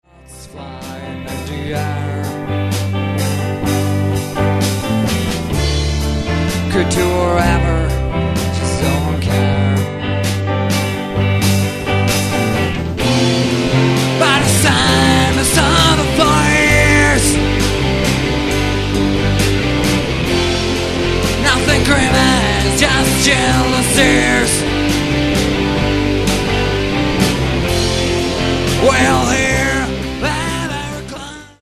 Nahráno živě na koncertu v klubu Prosek dne 22.3.2002